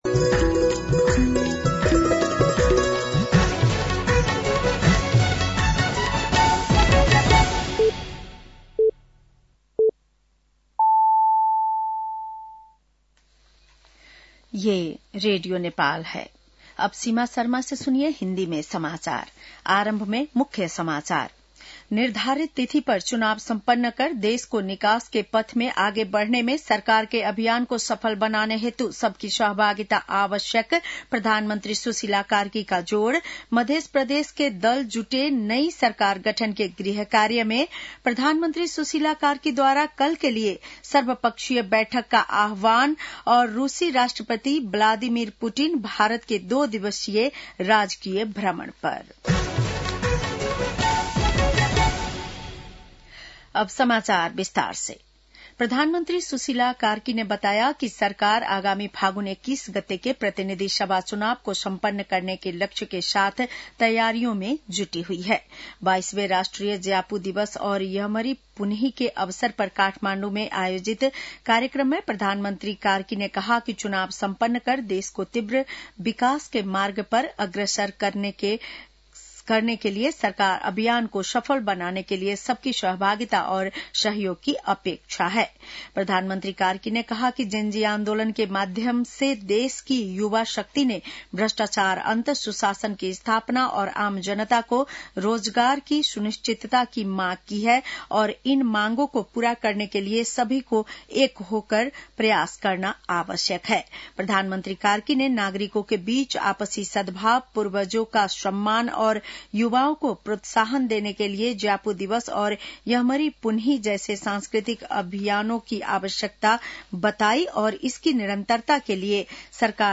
बेलुकी १० बजेको हिन्दी समाचार : १८ मंसिर , २०८२
10-PM-Hindi-NEWS-.mp3